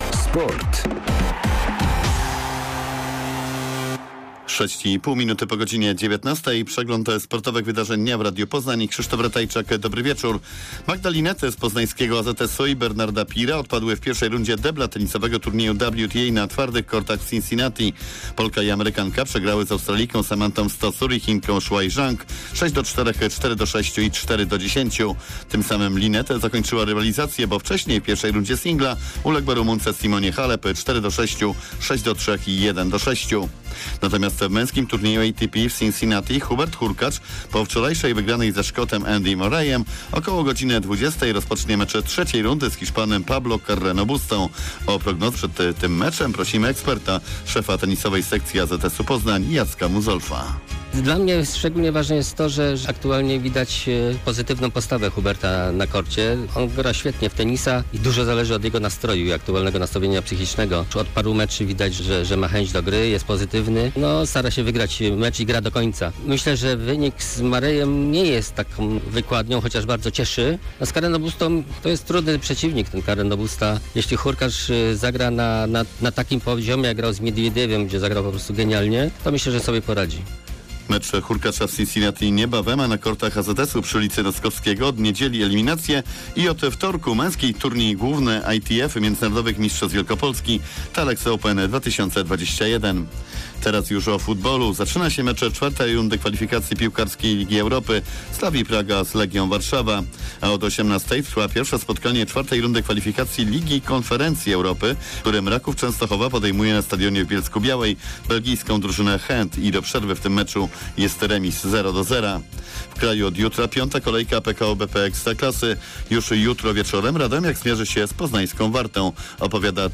19.08.2021 SERWIS SPORTOWY GODZ. 19:05